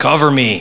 voice_coverme.wav